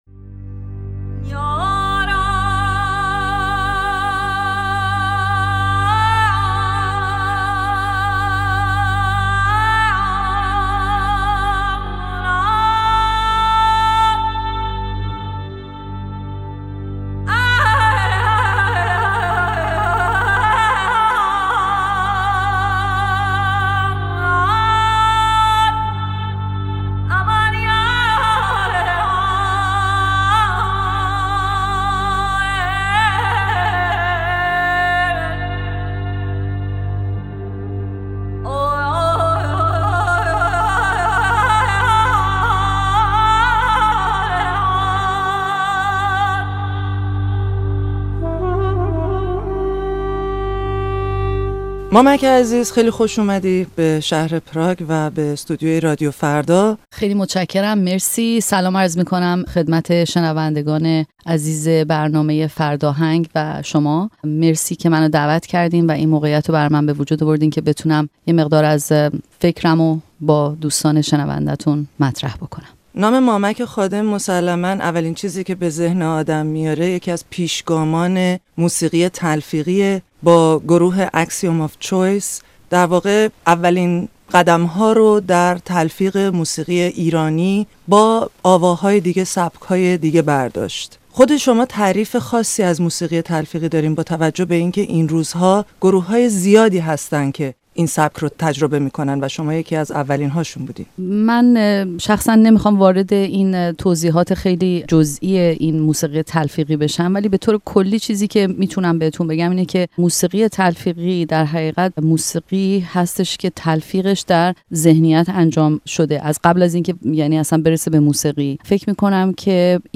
فردآهنگ ۹۸؛ گفت‌و‌گو با مامک خادم